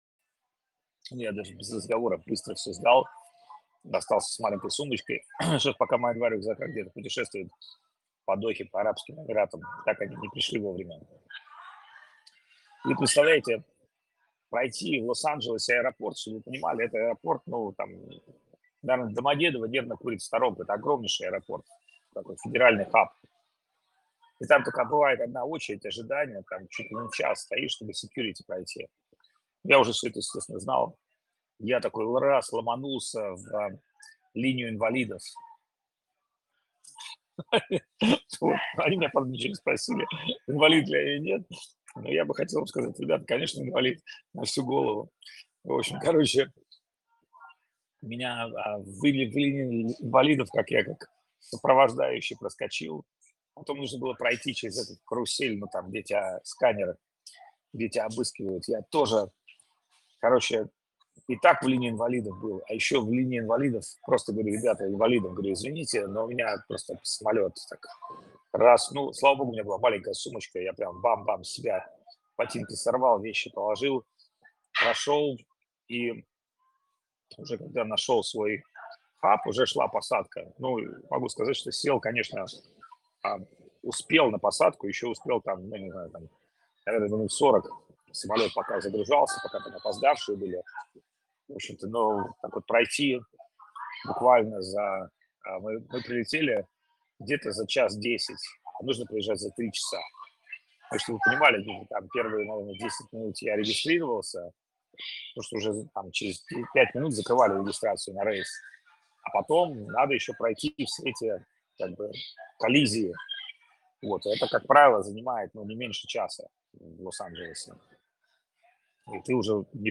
Место: Непал
Лекции полностью